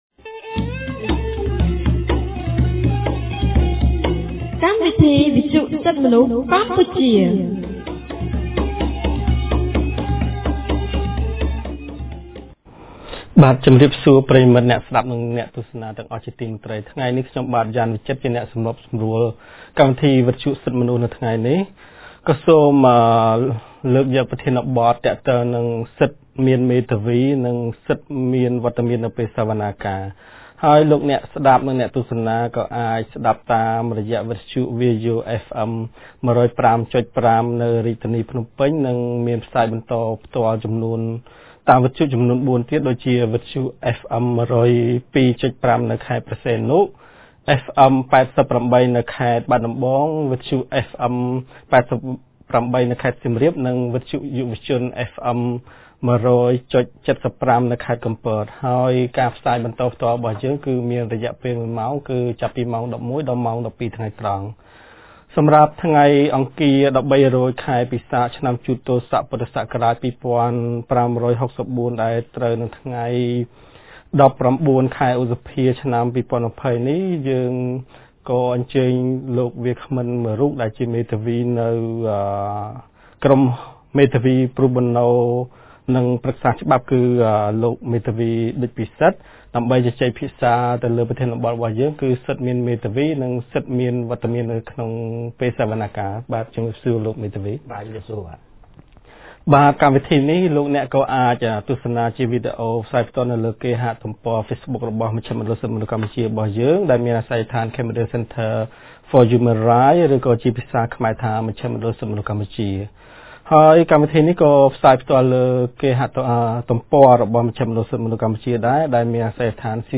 កាលពីថ្ងៃអង្គារ ១៣រោច ខែពិសាខ ឆ្នាំជូត ទោស័ក ព.ស២៥៦៤ ត្រូវនឹងថ្ងៃទី១៩ ខែឧសភា ឆ្នាំ២០២០ គម្រាងសិទ្ធិទទួលបានការជំនុំជម្រះដោយយុត្តិធម៌នៃមជ្ឈមណ្ឌលសិទ្ធិមនុស្សកម្ពុជា បានរៀបចំកម្មវិធីវិទ្យុក្រោមប្រធានបទស្តីពី សិទ្ធិមានមេធាវី និងមានវត្តមាននៅពេលសវនាការ។